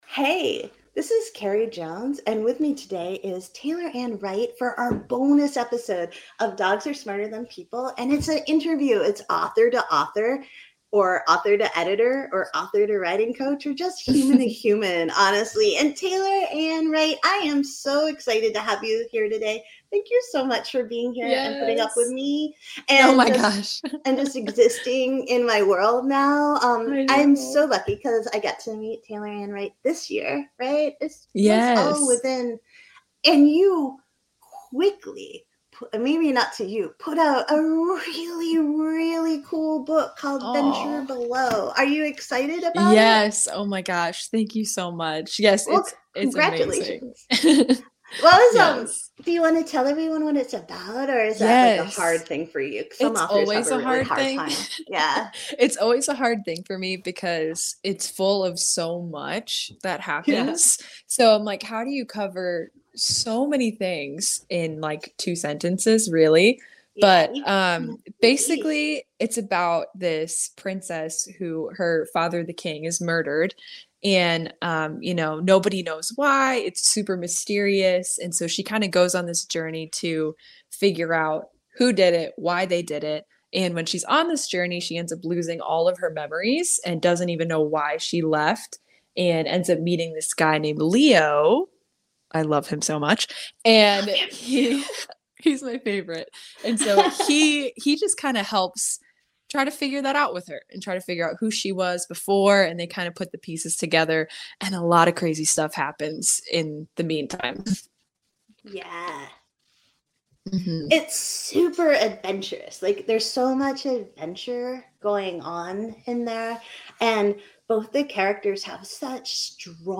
Bonus Author Interview